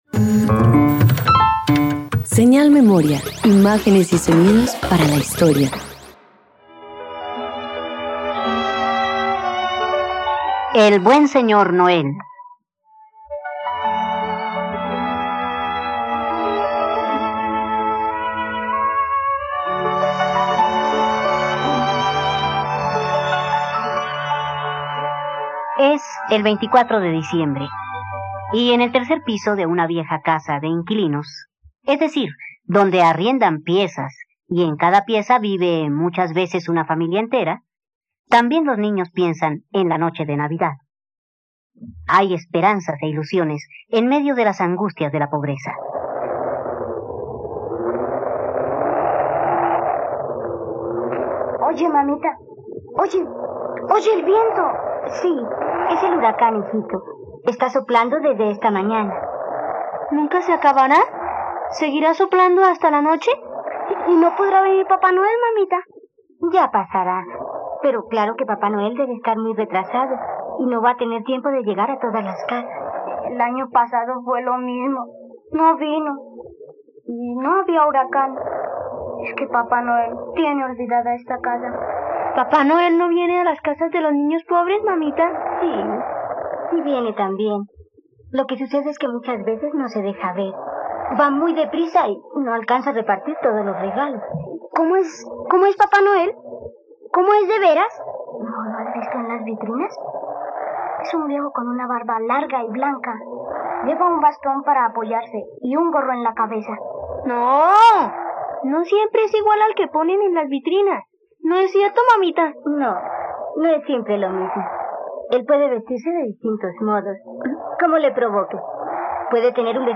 El buen señor Noel - Radioteatro dominical | RTVCPlay
Sinopsis Esta es una adaptación para radio del cuento “El buen señor Noel” del escritor Francés André́ Lichtenberger, donde narra la historia de una familia muy pobre, que en la víspera de navidad espera con ansias la llegada de Papá Noel.